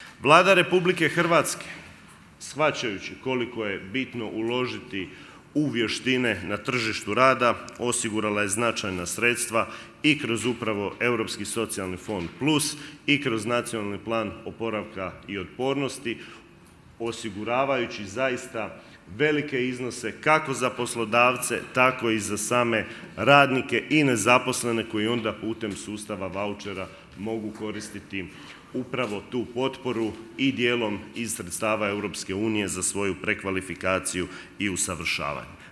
Konferenciju je kao domaćin otvorio resorni ministar Marin Piletić, a potom je za govornicu stao i ministar vanjskih i europskih poslova Gordan Grlić Radman: